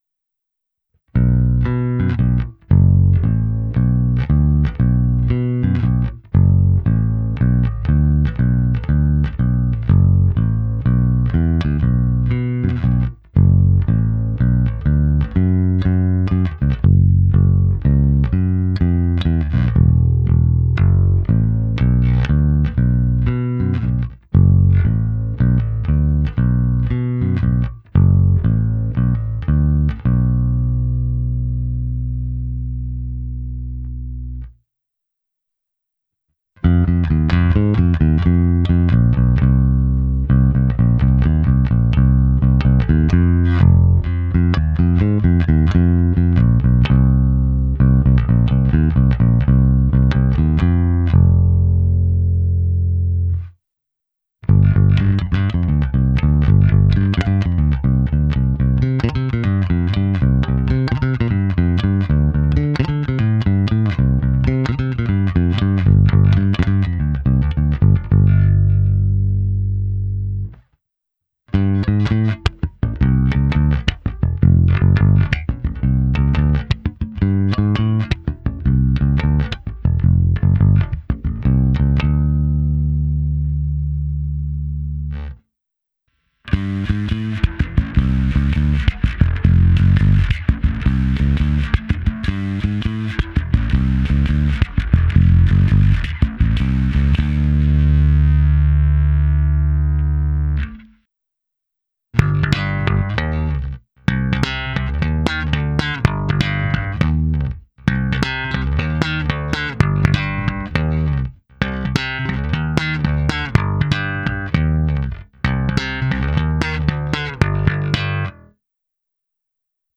Ukázka se simulací aparátu prostřednictvím Zoom MS-60B, použito zkreslení a slap, při prstové technice hráno nad snímačem.